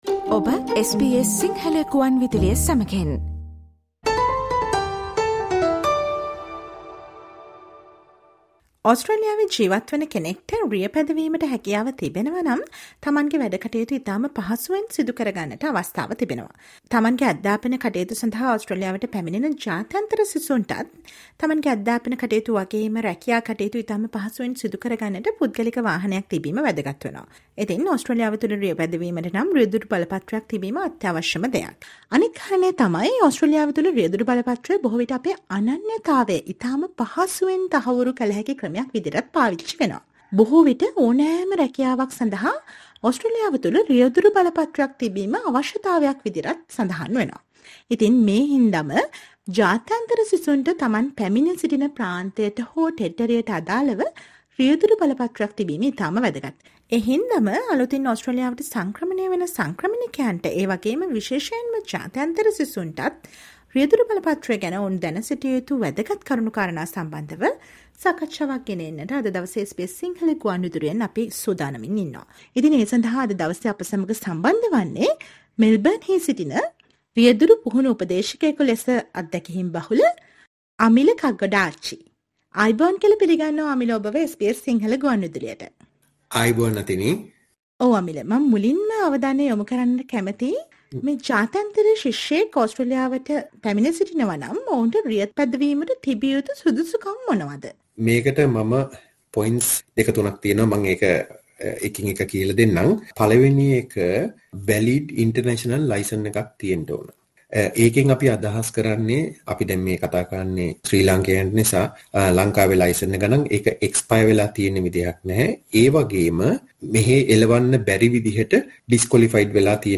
Listen to this SBS Sinhala interview on how to convert your overseas driver's license in Australia and how to obtain a new Australian driver's lisence if you don't havr one.